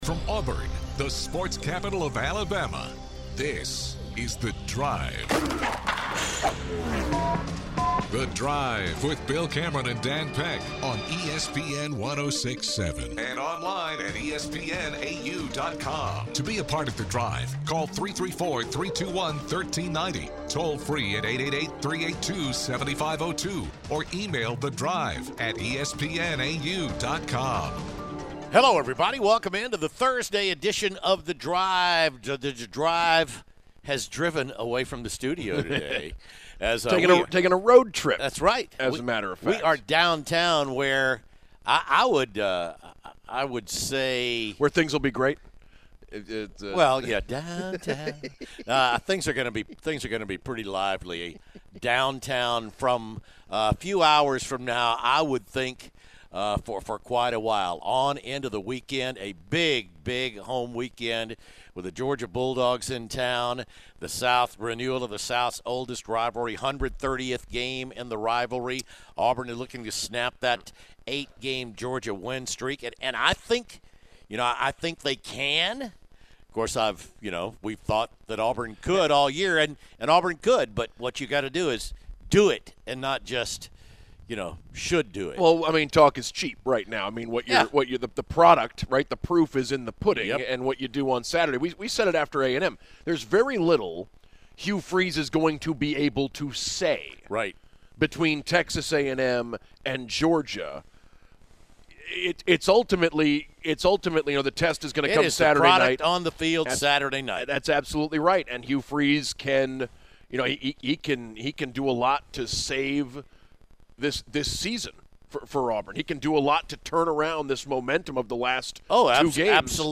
The Drive Is Live From Skybar